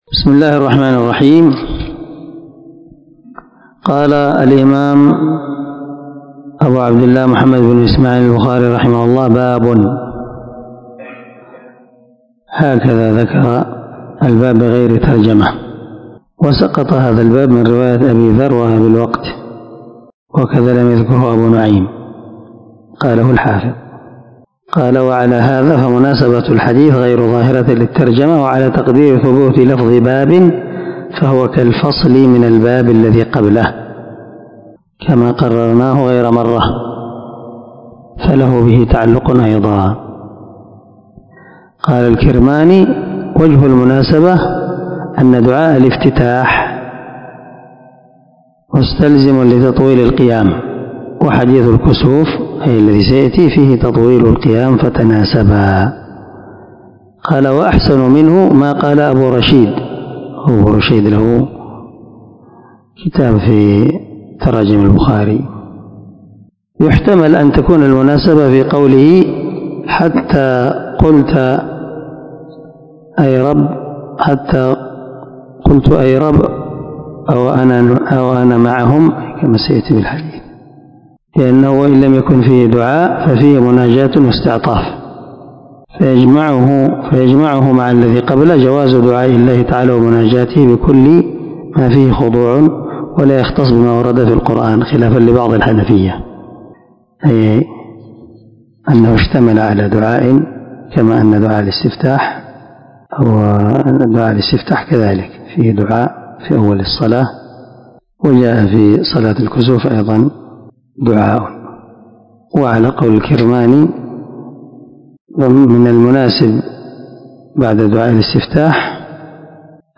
490الدرس 73من شرح كتاب الأذان حديث رقم ( 745 ) من صحيح البخاري